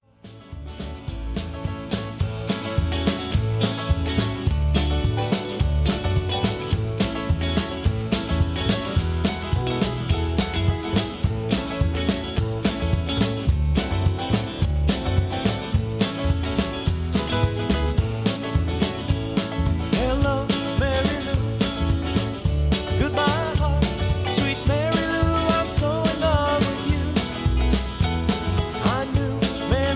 Key-E
Just Plain & Simply "GREAT MUSIC" (No Lyrics).